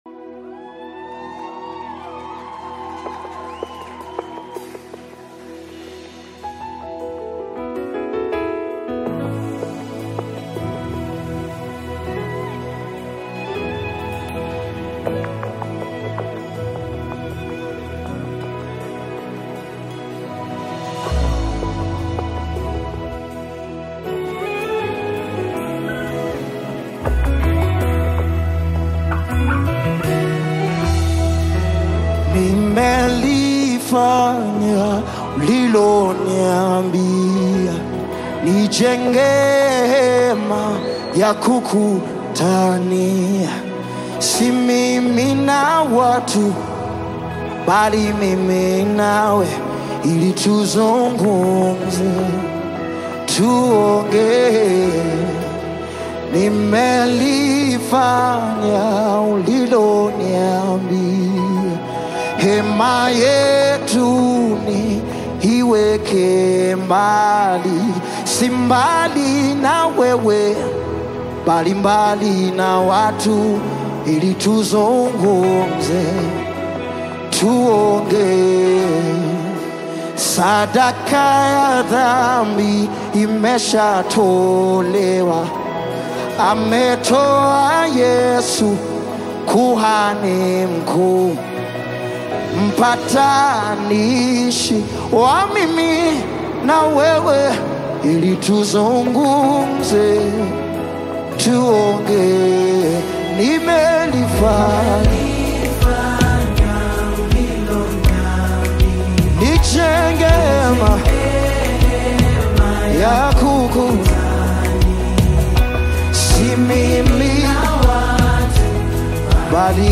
Nyimbo za Dini music
Gospel music track
Tanzanian Gospel artist, singer, and songwriter